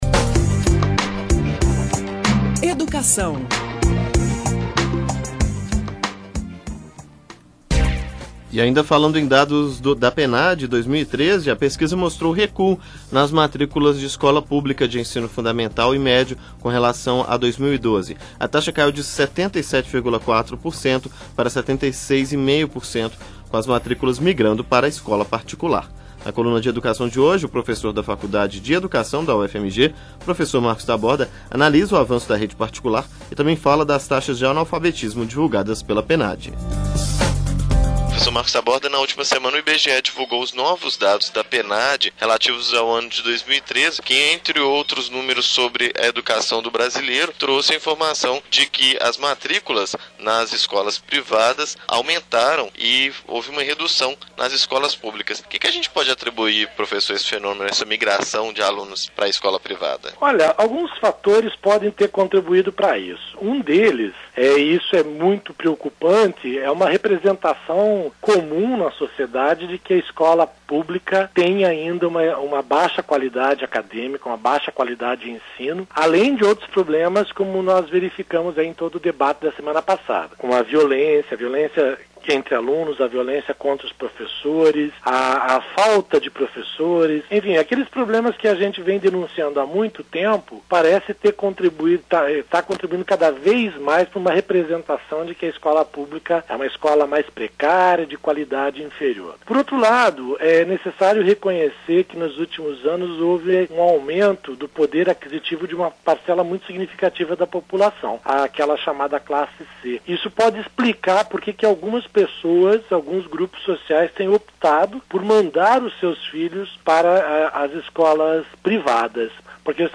Educação: Pesquisa Nacional por Amostra de Domicílios mostra recuo nas matrículas de escolas públicas nas escolas de ensino fundamental e médio e avanço nas escolas privadas. Especialista analisa os resultados da pesquisa.